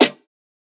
Snares
SN_Killer.wav